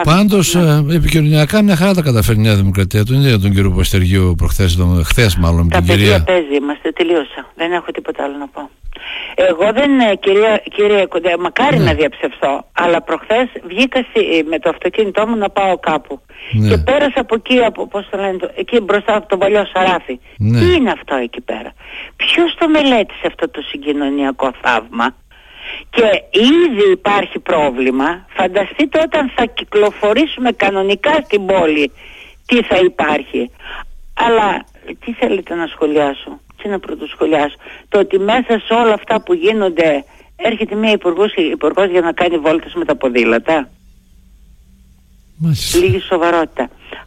Καταπέλτης κατά του δημάρχου κ. Παπαστεργίου, αλλά και της Δόμνας Μιχαηλίδου η πρώην βουλευτής του ΠαΣοΚ Σούλα Μερεντίτη με αφορμή την ποδηλατάδα: «Τα παιδία παίζει» είπε στην εκπομπή «Χαμηλές Πτήσεις».